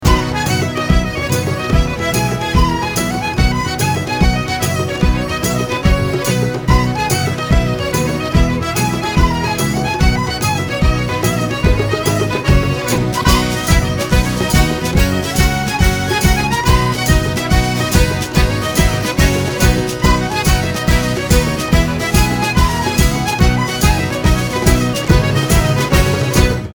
• Качество: 320, Stereo
веселые
без слов
инструментальные
русский рок
рок-баллада